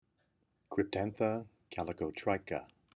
Pronunciation/Pronunciación:
Cryp-tán-tha  ca-ly-co-trì-cha